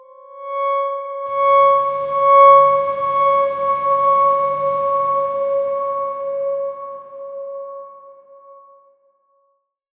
X_Darkswarm-C#5-mf.wav